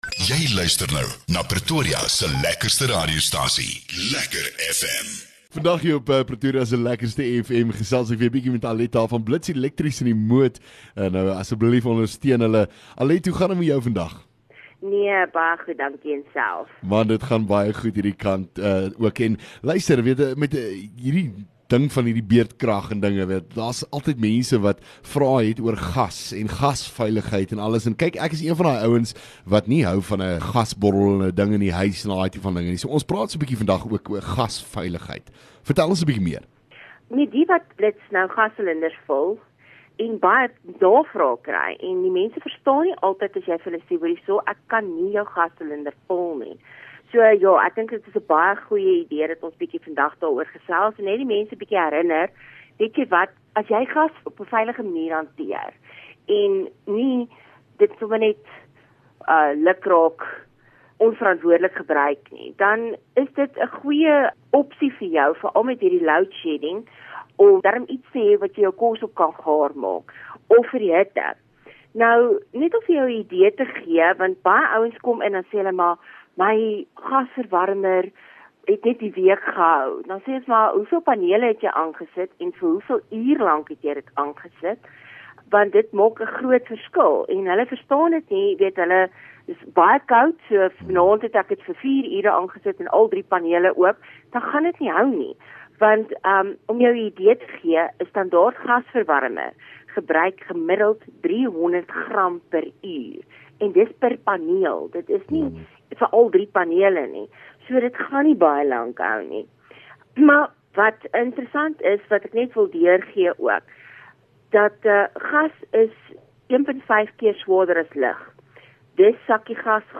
LEKKER FM | Onderhoude 5 Jun Blits Elektrisiëns